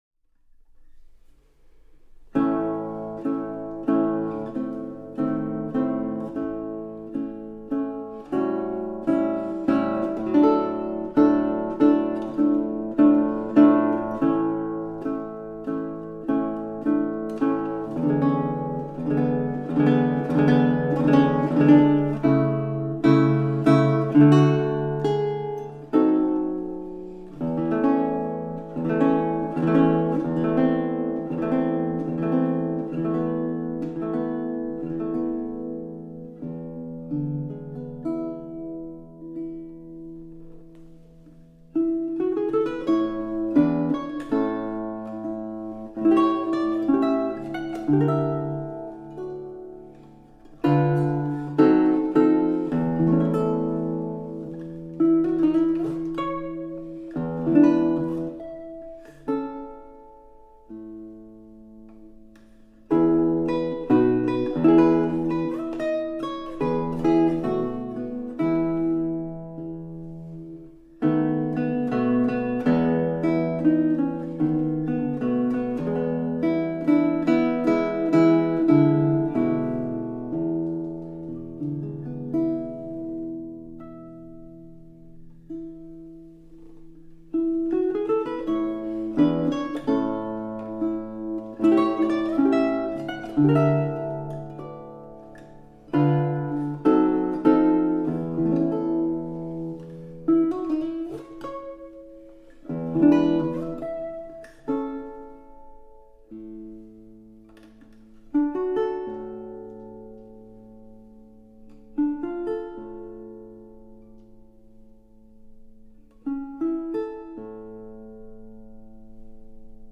Gitarre, Petitjean, Mirecourt, um 1800, Kat.-Nr. 5119